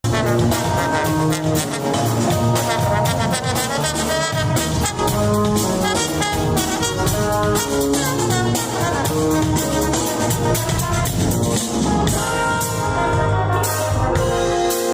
For the second straight year, the festival began with a special concert inside of the Emporia Granada Theatre Friday night. The evening featured performances by local band Daydream